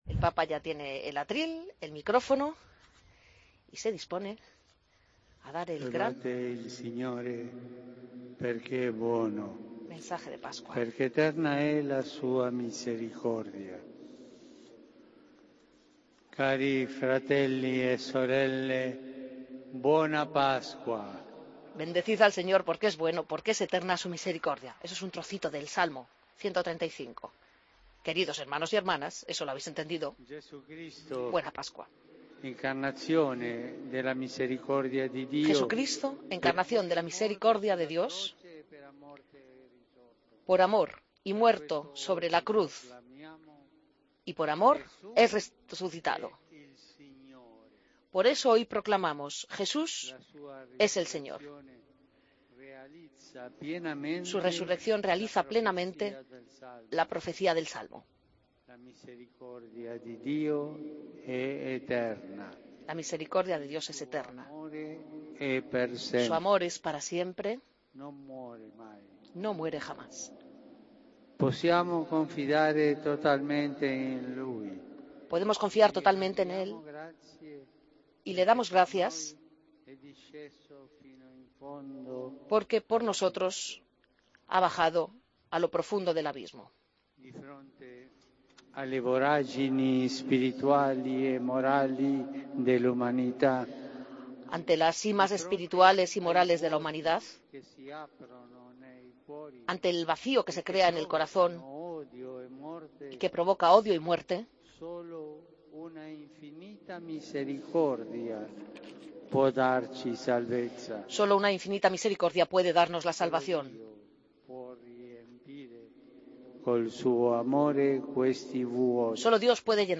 Escucha la retransmisión en directo de la Bendición 'Urbi et Orbi' del Papa Francisco tras la Misa del Domingo de Resurección